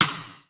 Index of dough-samples/ tidal-drum-machines/ machines/ AlesisSR16/ alesissr16-rim/
Rim.wav